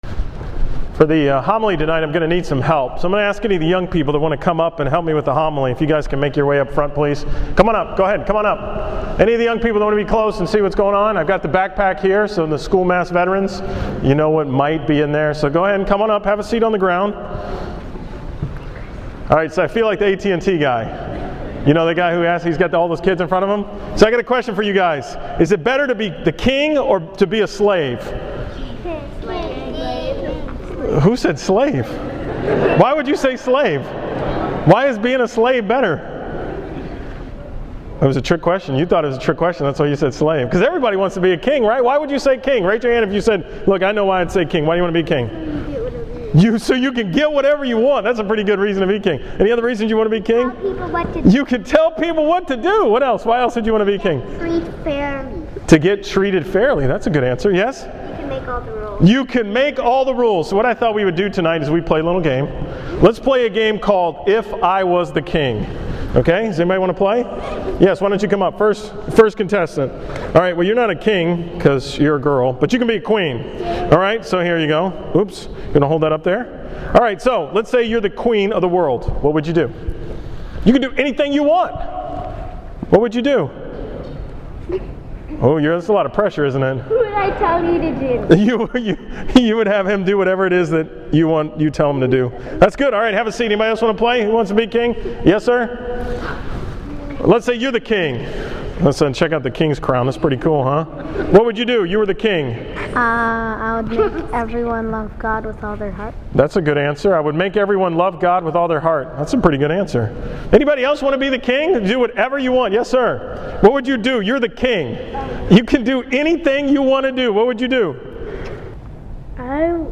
From the 5 pm Mass on Sunday, November 24th
Category: 2013 Homilies